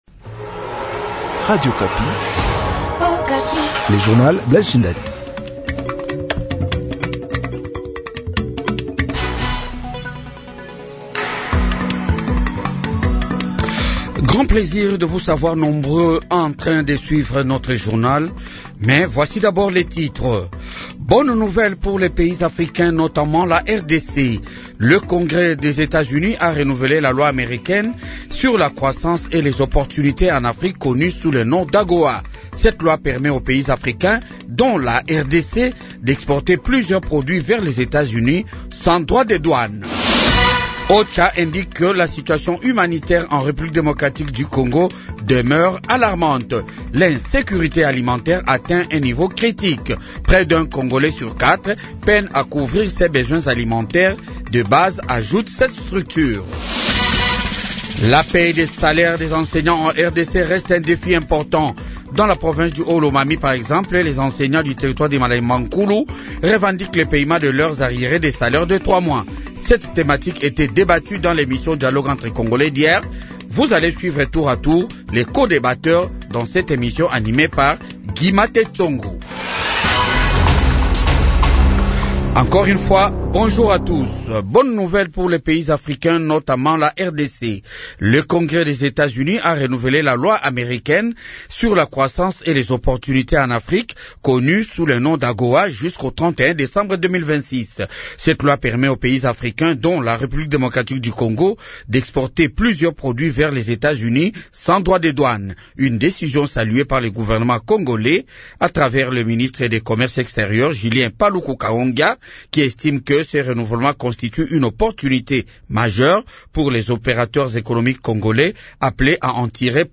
Journal du matin 8h